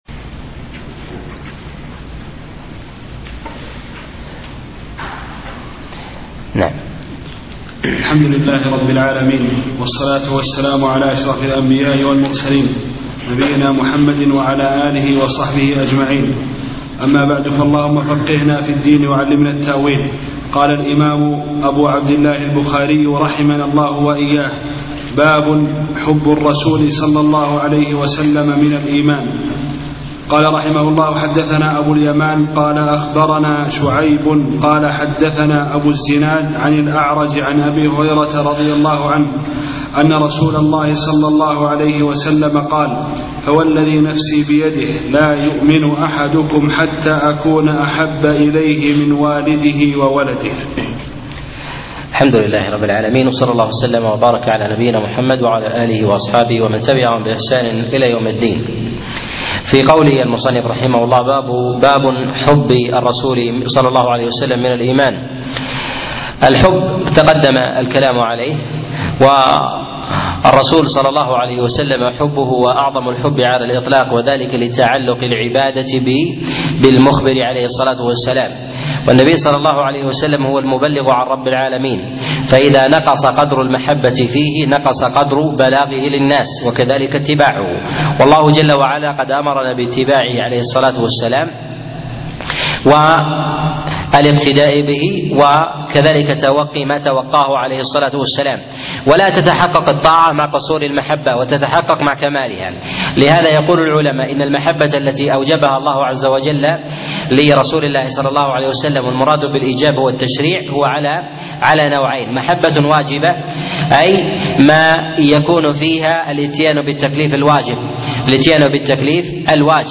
شرح كتاب الإيمان من صحيح البخاري الدرس 2